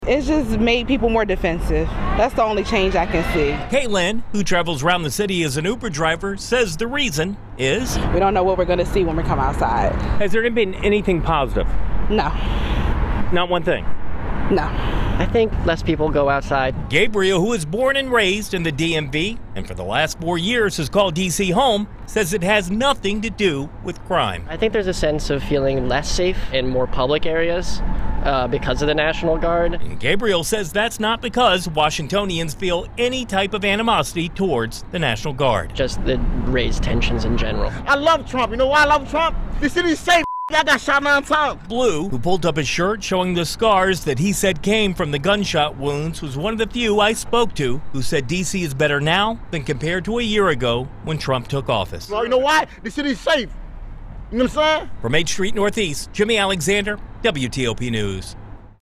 So it wasn’t surprising that in 90 minutes of conversations on H Street, only one D.C. resident told WTOP he thought Trump’s first year back in office has been positive for the District.